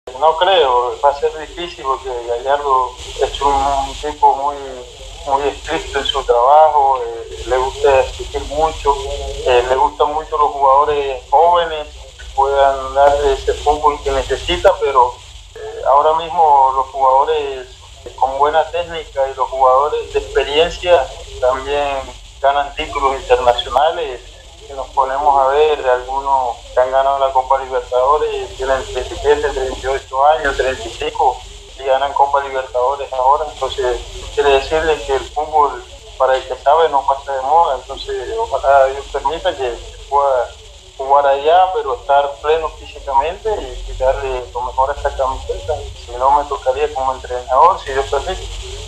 (Teófilo Gutiérrez en TyC Sports)
Quiero decirle que para quien sabe, el fútbol no ha pasado de moda y Dios permita volver allá en la mejor condición física, o sino hacerlo como entrenador”, puntualizó ‘Teo’ en dialogo con TyC Sports de Buenos Aires.